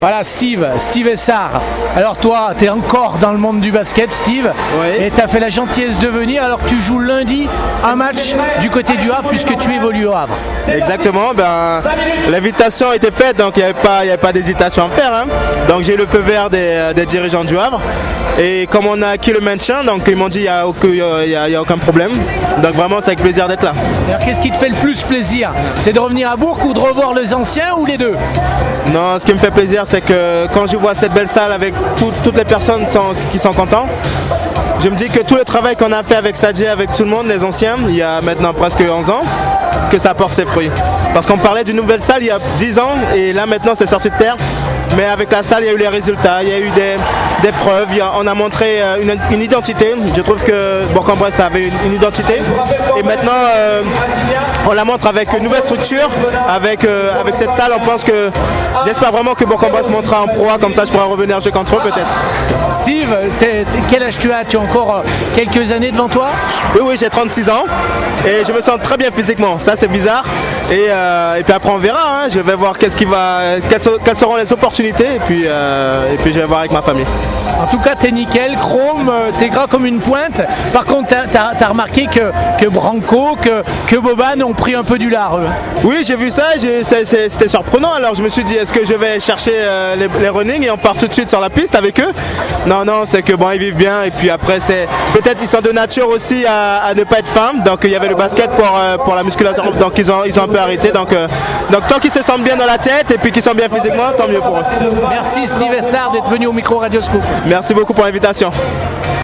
Retrouvez les réactions d’après-match au micro Radio Scoop